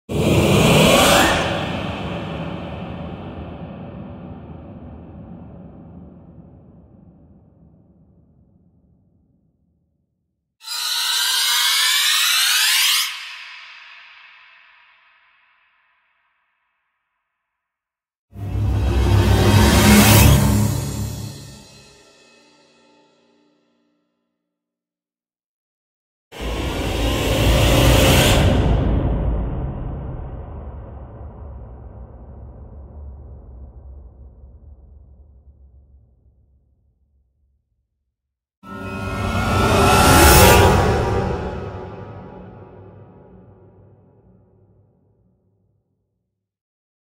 دانلود آهنگ ترسناک 5 از افکت صوتی طبیعت و محیط
جلوه های صوتی
دانلود صدای ترسناک 5 از ساعد نیوز با لینک مستقیم و کیفیت بالا